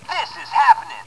For a preview of the sound clips from the Toys R Us exclusive version, click on the three sound module bases below.